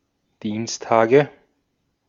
Ääntäminen
Ääntäminen Tuntematon aksentti: IPA: /ˈdiːnstaːɡə/ Haettu sana löytyi näillä lähdekielillä: saksa Käännöksiä ei löytynyt valitulle kohdekielelle. Dienstage on sanan Dienstag monikko.